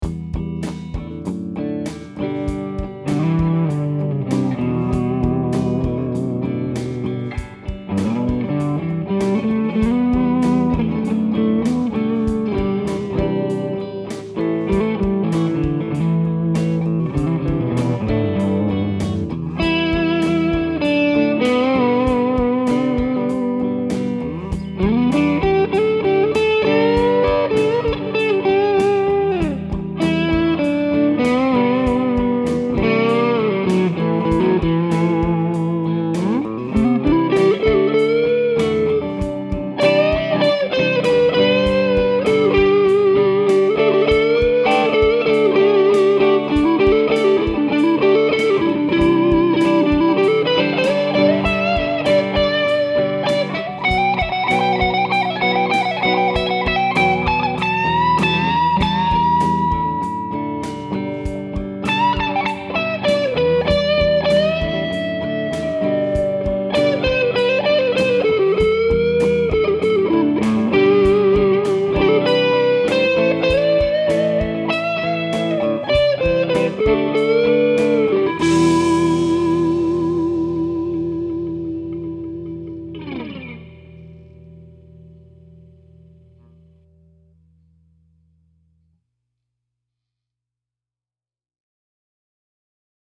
The rhythm riff was played in the neck pickup, and I played the lead in the bridge pickup with the ‘bucker tapped to get a slightly spanky single-coil tone. My Timmy pedal provides the drive for the lead, and both tracks were played through my Fender Hot Rod Deluxe in the clean channel – completely clean, mind you. I added a bit of spring reverb from the amp to each track.
Man! What a sweet sound!